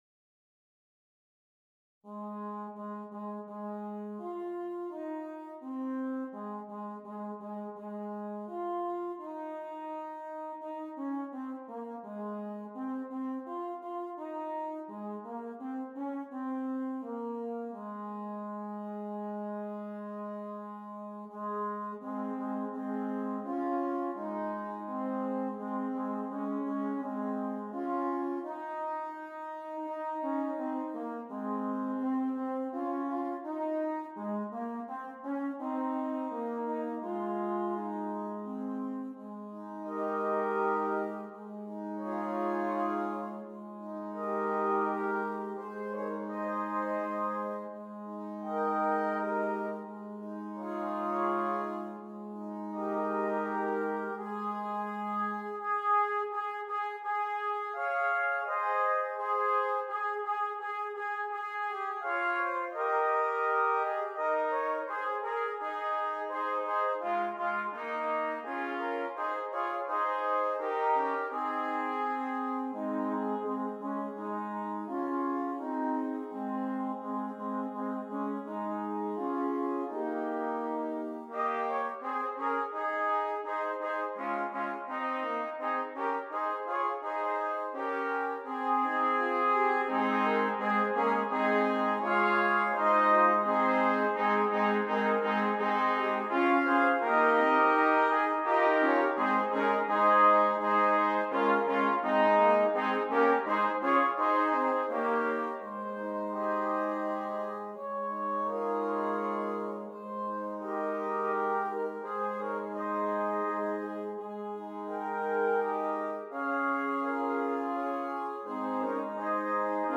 6 Trumpets
Traditional Carol
This piece is flowing and smooth, quiet and reflective.